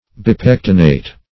Meaning of bipectinate. bipectinate synonyms, pronunciation, spelling and more from Free Dictionary.
Search Result for " bipectinate" : The Collaborative International Dictionary of English v.0.48: Bipectinate \Bi*pec"ti*nate\, Bipectinated \Bi*pec"ti*na`ted\, a. [Pref. bi- + pectinate.]